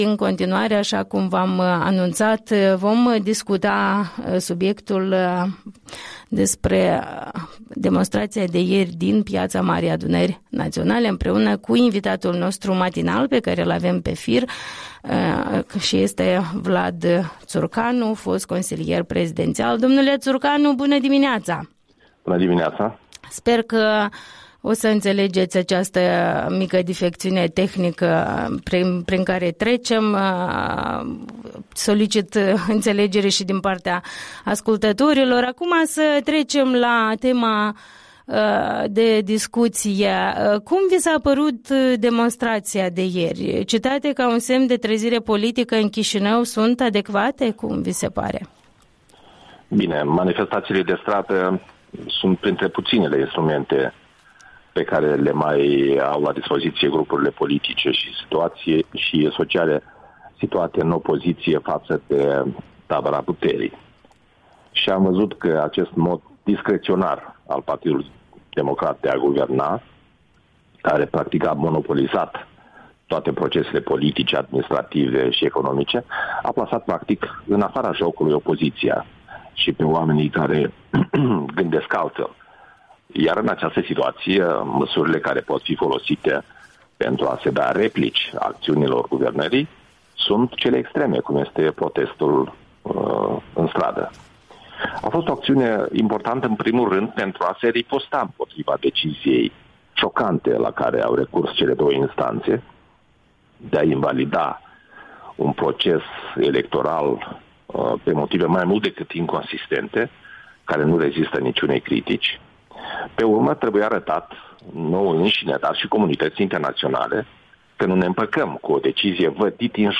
Interviul dimineții cu fostul consilier prezidențial despre evenimentele în curs la Chișinău.
Interviul dimineții: cu Vlad Țurcanu